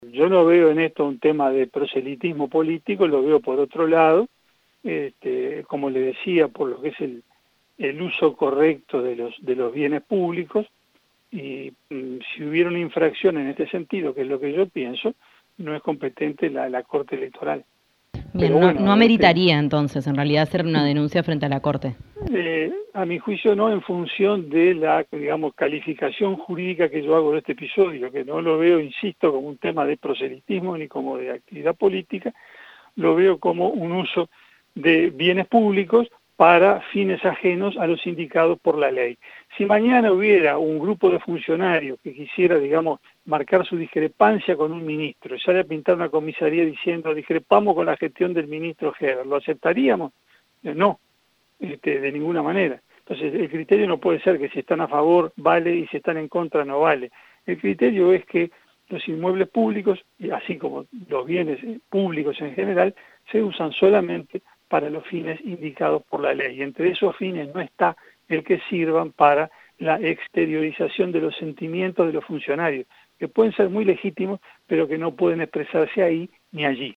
«No se puede utilizar un inmueble el Estado para que un grupo de funcionarios exteriorice un sentimiento, que es muy legítimo y plenamente compartible», expresó el legislador en diálogo con 970 Noticias.